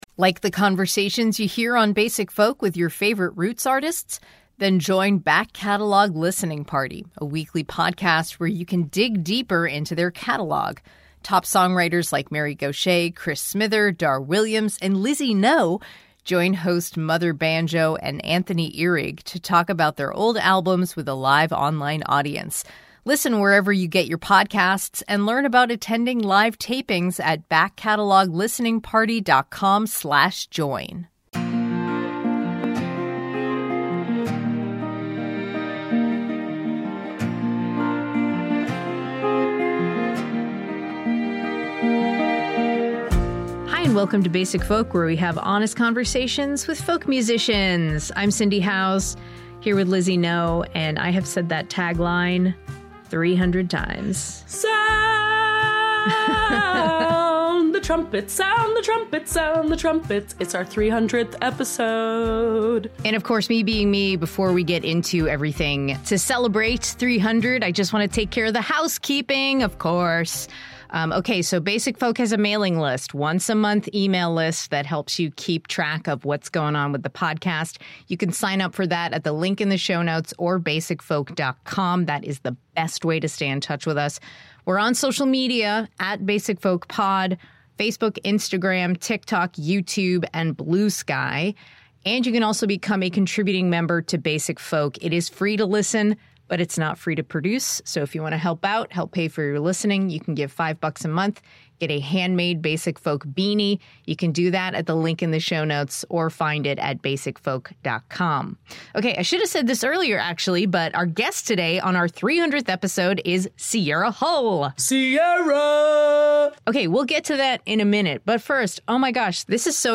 Celebrate #300, featuring GRAMMY nominee Sierra Hull as our guest, with us below.)